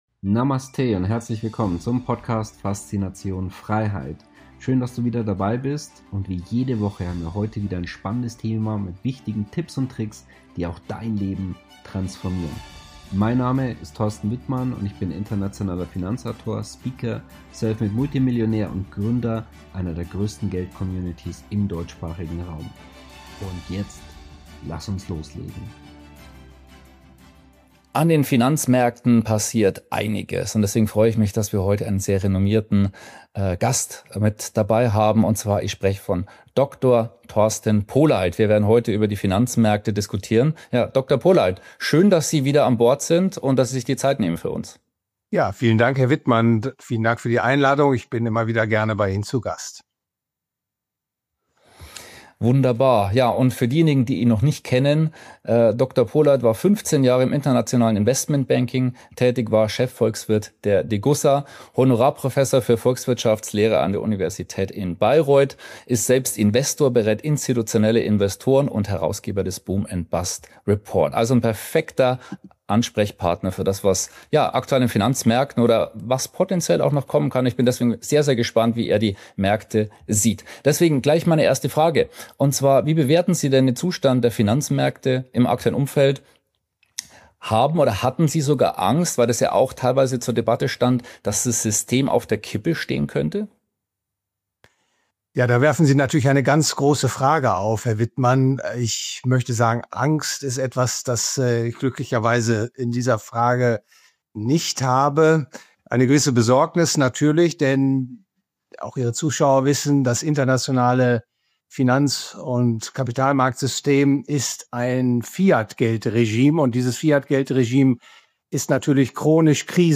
Interview mit Prof. Dr. Polleit und seine klare Prognose zu Edelmetallen!